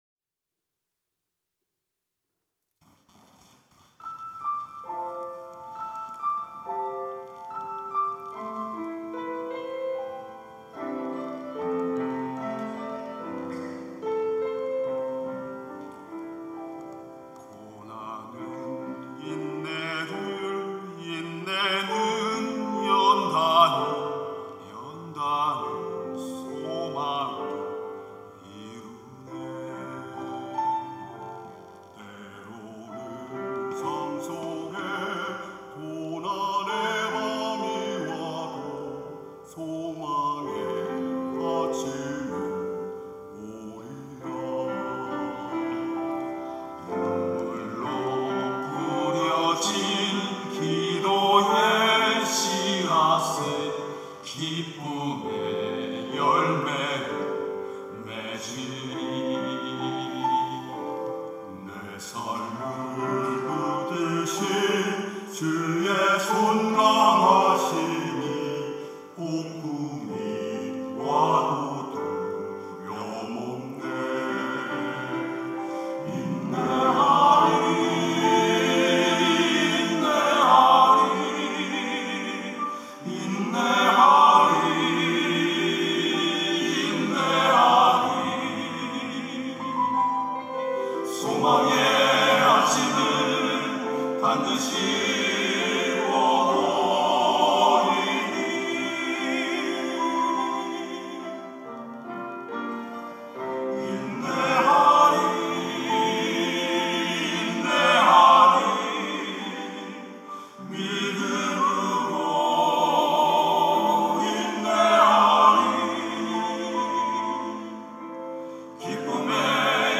천안중앙교회
찬양대 휘오스